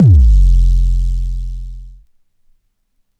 Index of /archive/TRUTH-HZ/2023_TRUTH-HZ_USB/Sample Packs/Crowdsourced Sample Pack/Drum Kit/808s & Subs